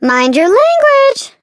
flea_kill_vo_03.ogg